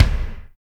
TUNNEL K.wav